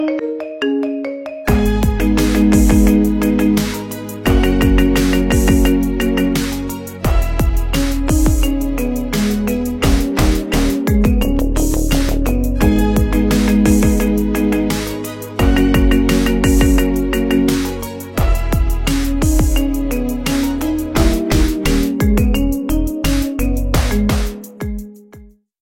Categoria Marimba Remix